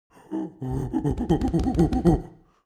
Scared Monkey Thumping Chest Sound Button - Free Download & Play